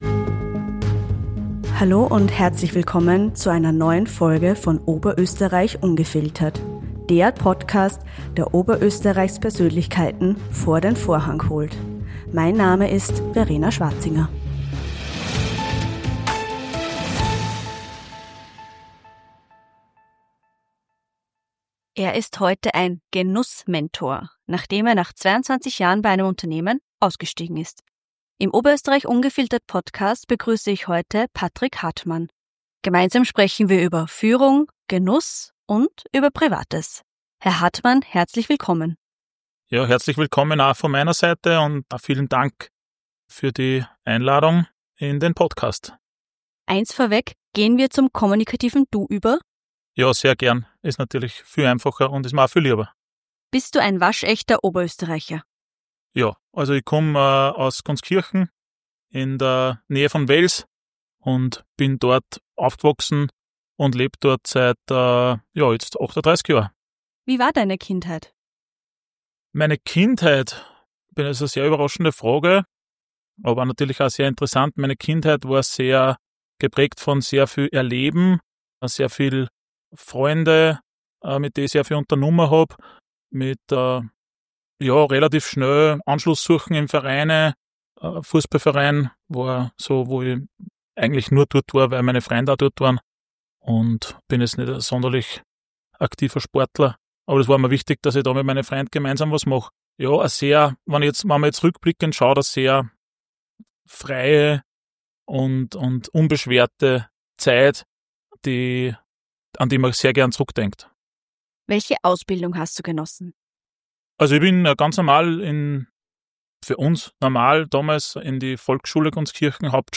Das Gespräch liefert Einblicke in die Herausforderungen des Sprungs ins Unternehmertum, die Entwicklung innovativer Genussformate, das Jonglieren mit Bürokratie und Online-Marketing und persönliche Wege zu Ausgleich und Zufriedenheit.